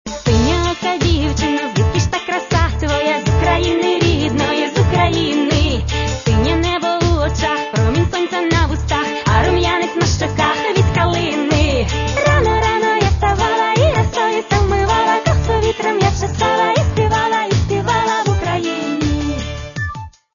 Каталог -> Поп (Легка) -> Лірична